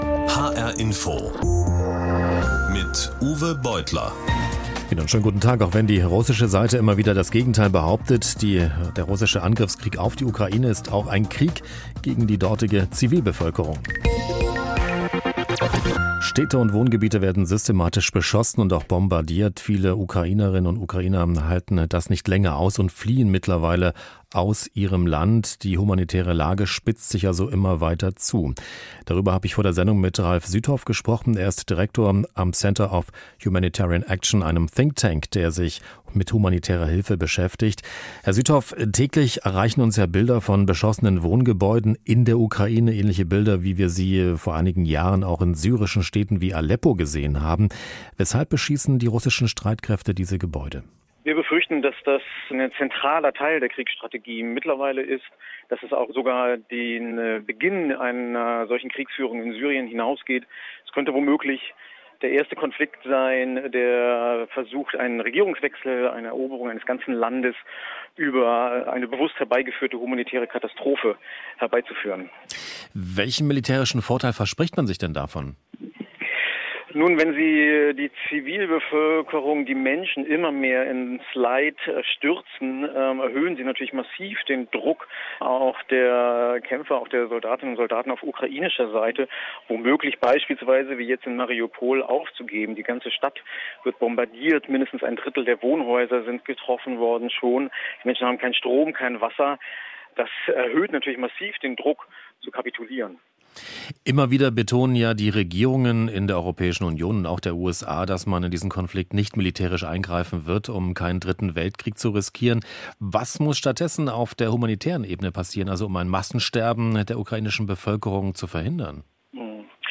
Recording of the radio programme on hr-INFO on 21 March 2022: